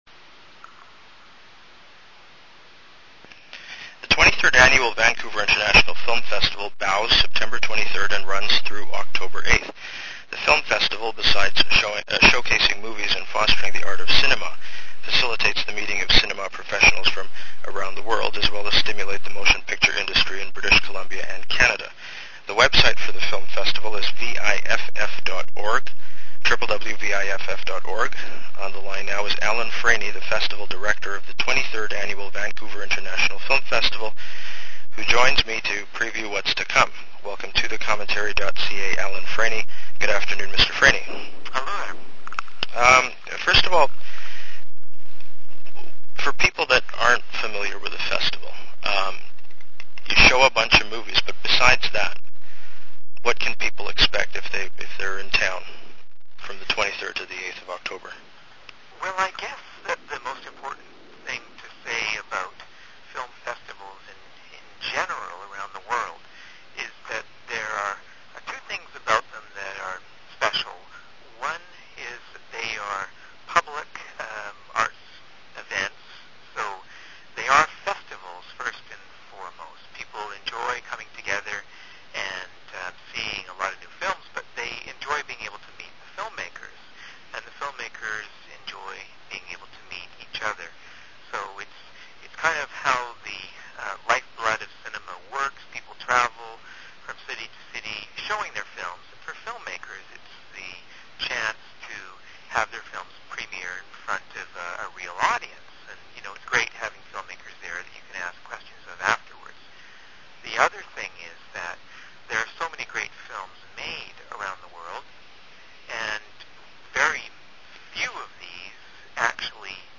On the line now